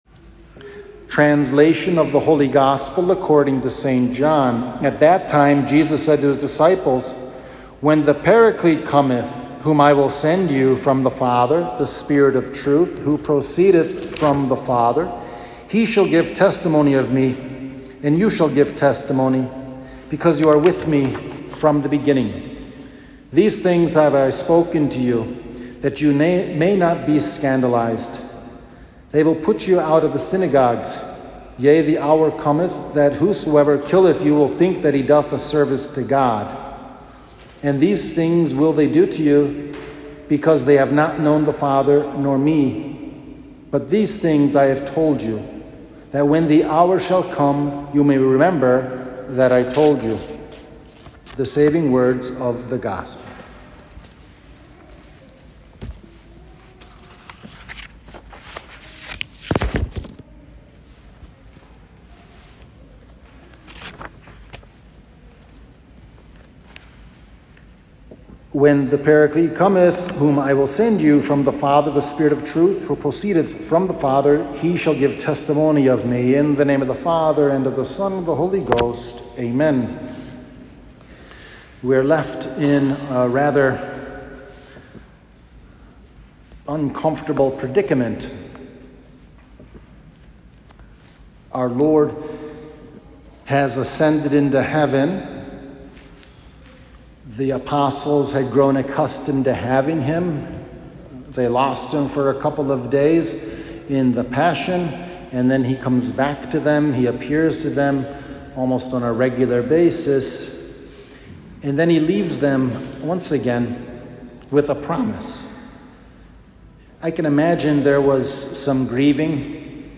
Sunday After Ascension — Homily